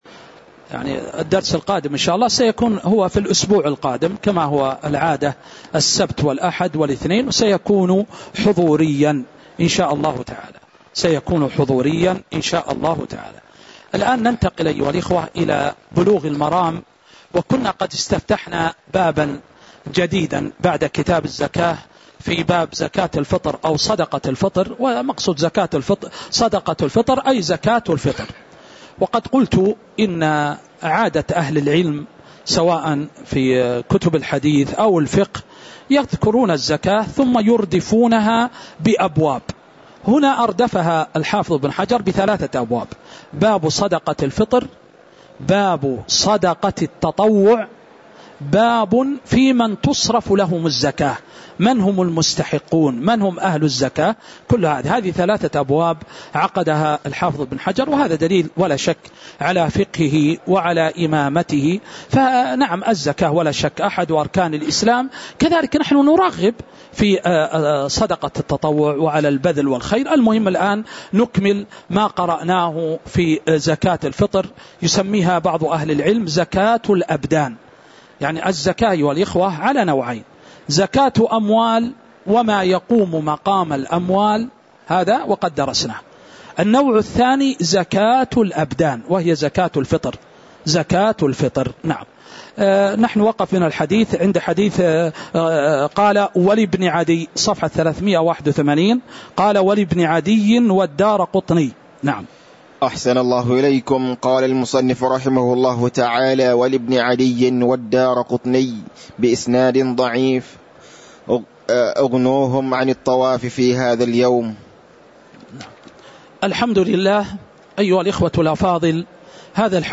تاريخ النشر ١٣ شوال ١٤٤٥ هـ المكان: المسجد النبوي الشيخ